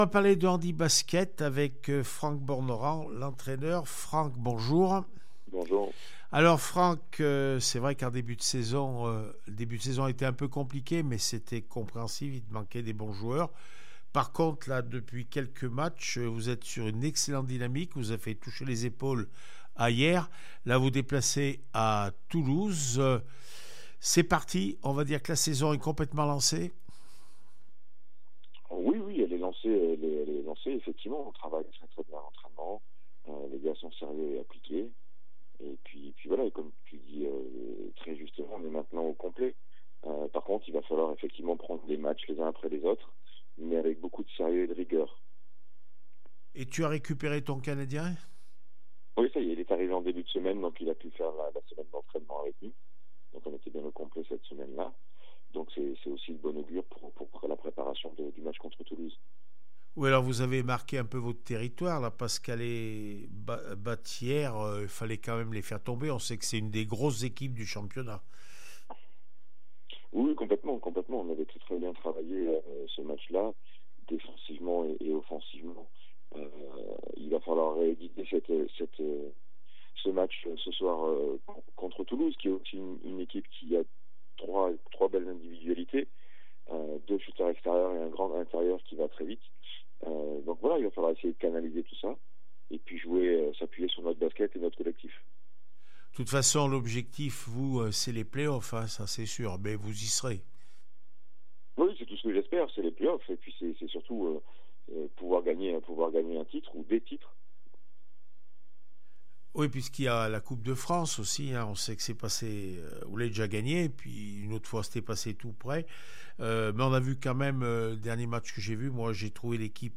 2 décembre 2023   1 - Sport, 1 - Vos interviews